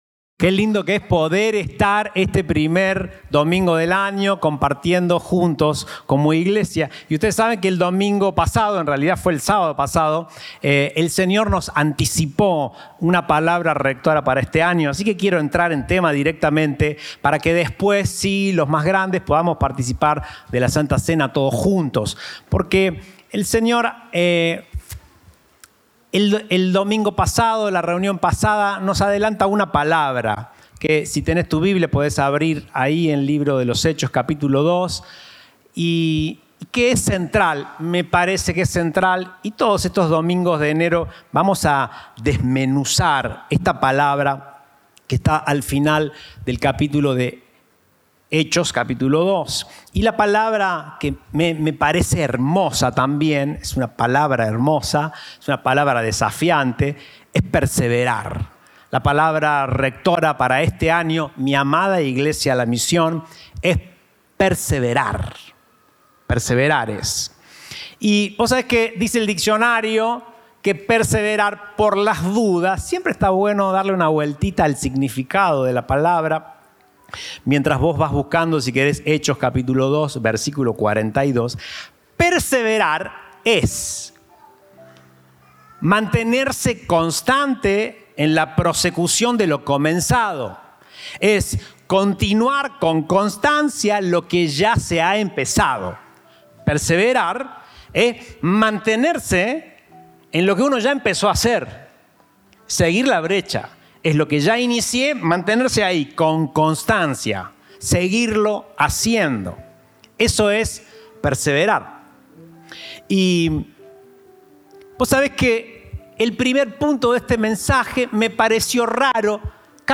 Compartimos el mensaje del Domingo 7 de Enero de 2024